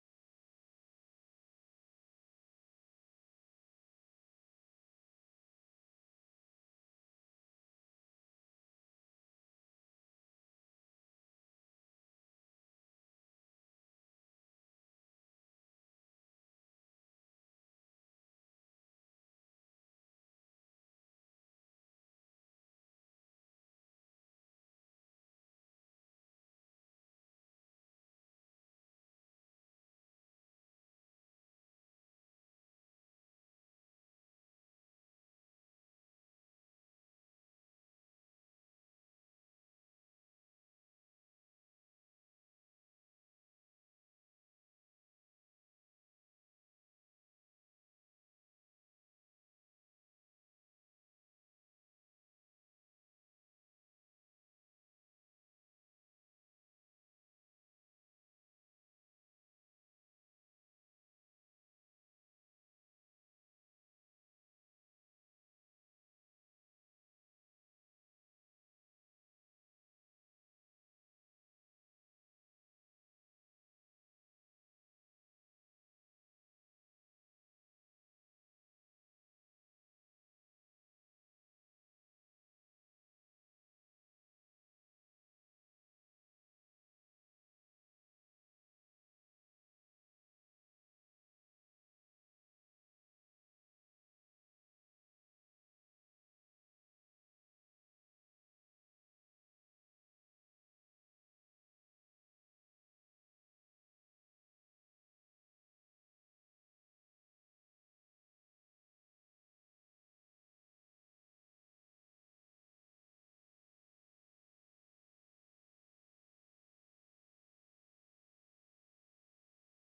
مراسم عزاداری محرم شب پنجم ۱۱ مرداد مـــــــــاه ۱۴۰۱ - موکب‌الحسین
مراسم عزاداری شب پنجم محرم الحرام ۱۴۴۴